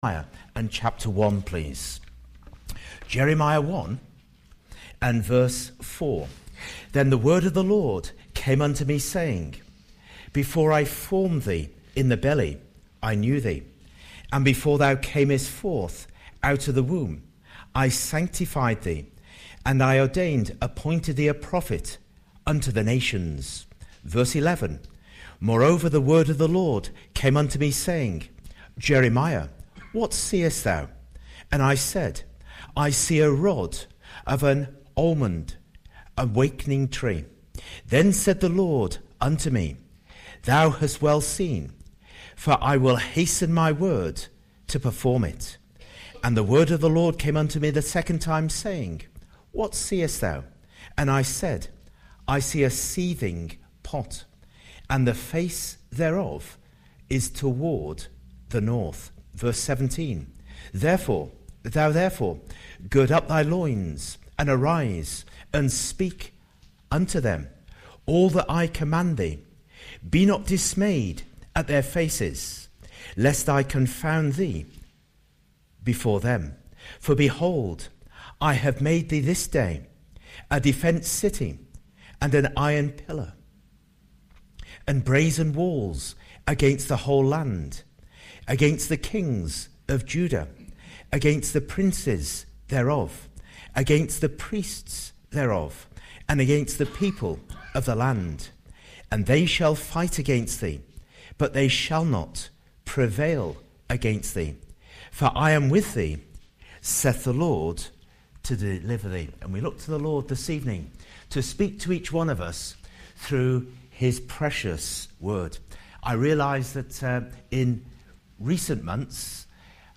Service Type: Report